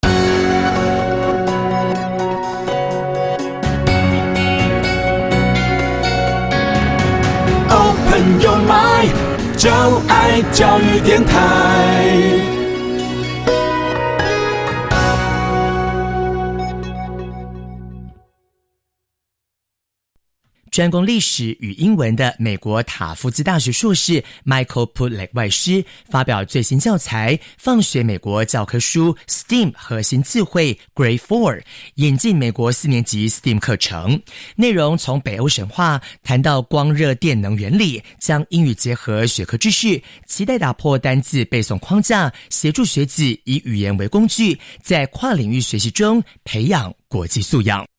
掃描書封QR Code下載「寂天雲」App，即能下載全書音檔，無論何時何地都能輕鬆聽取專業母語老師的正確道地示範發音，訓練您的聽力。